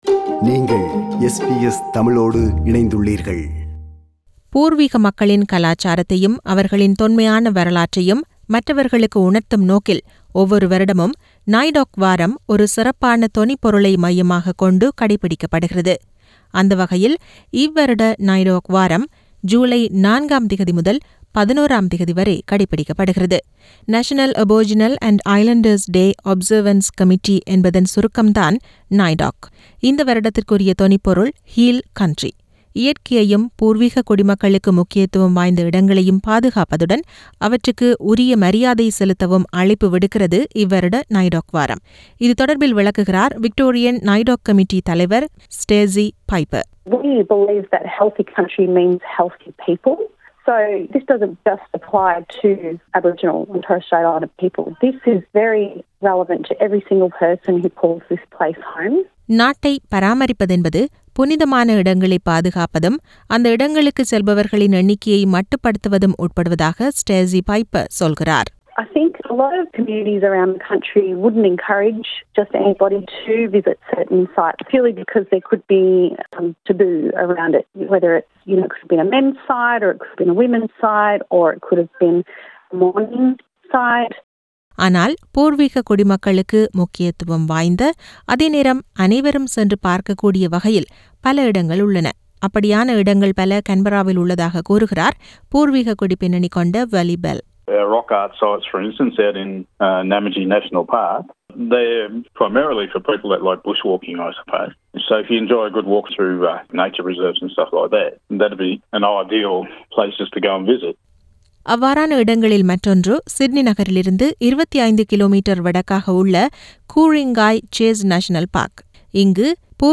பூர்வீக மக்களின் கலாச்சாரத்தையும் அவர்களின் தொன்மையான வரலாற்றையும் மற்றவர்களுக்கு உணர்த்தும் நோக்கில் ஒவ்வொரு வருடமும் NAIDOC வாரம் ஒரு சிறப்பான தொனிப்பொருளை மையமாகக் கொண்டு கடைப்பிடிக்கப்படுகிறது. அந்தவகையில் இவ்வருட NAIDOC வாரம் ஜுலை 4-11 ம் திகதி வரை கடைப்பிடிக்கப்படுகிறது. இதுகுறித்த விவரணம்.